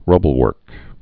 (rŭbəl-wûrk)